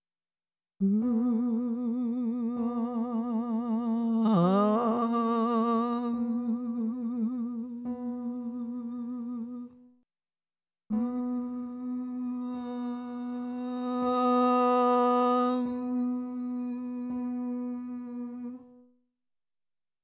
参考音源：失敗パターン
音量注意！
当たり前にメッサ・ディ・ボーチェなので、音源冒頭のようにどれだけ微妙でも断絶しちゃうとアウトです。
音源の後半部分はそもそも始めから小さい弱い地声でスタートしちゃってるパターンの失敗です。